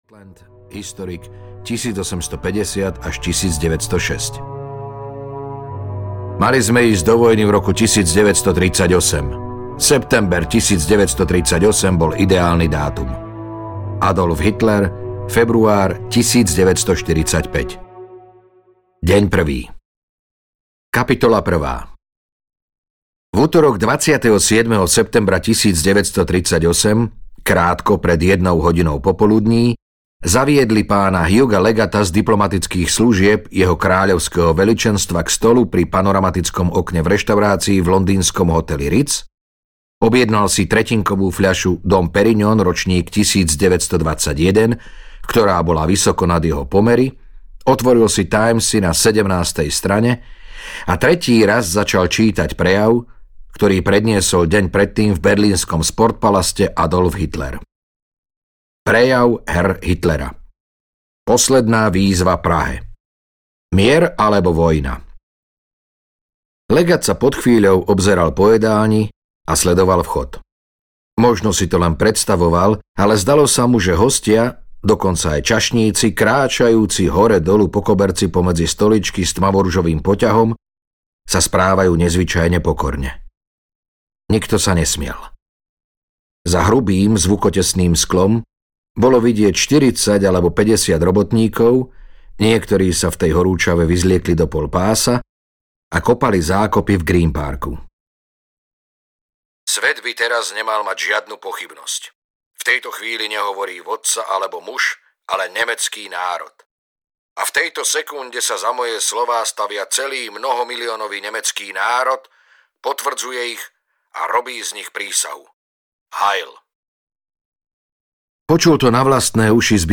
Mníchov audiokniha
Ukázka z knihy